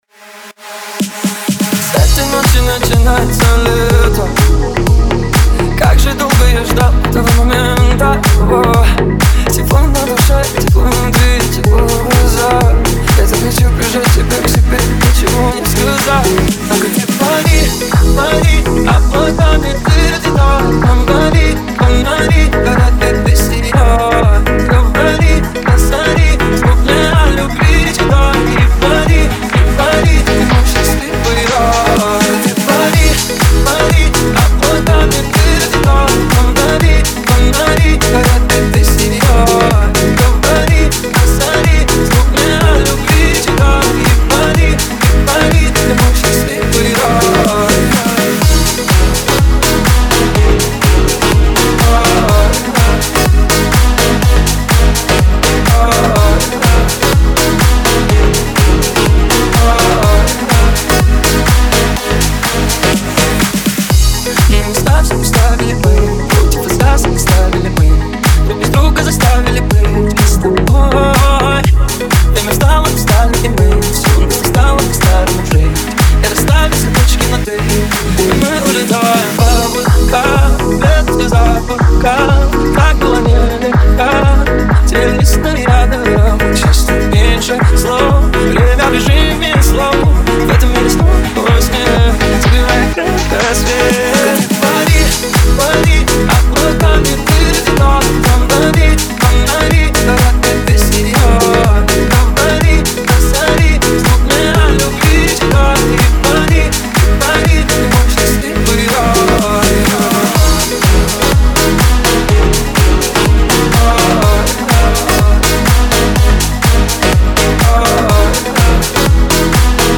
это яркий трек в жанре поп с элементами EDM